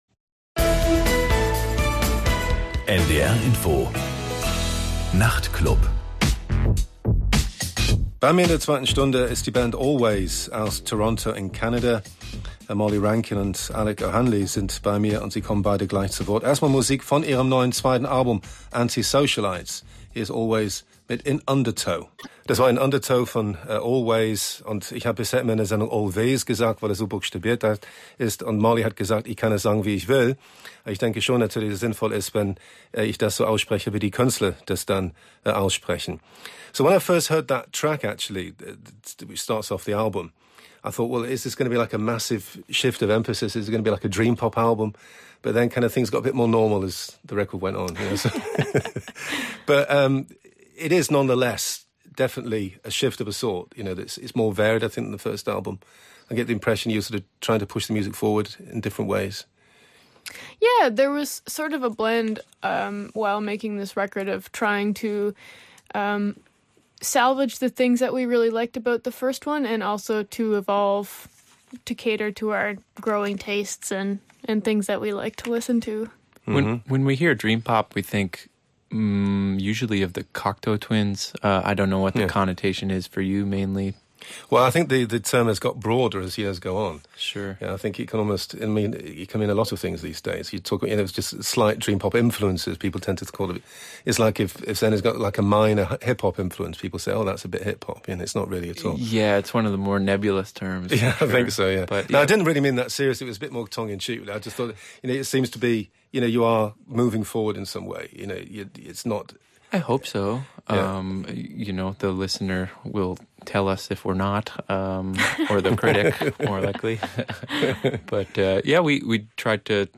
Interview: Molly Rankin und Alec O'Hanley (Alvvays) Typ: Aufzeichnung Sendung vom 29.10.2017 Länge: 31:09
– Aufzeichnung: Ähnlich wie die Live-Studio-Situation, nur eben tagsüber aufgezeichnet.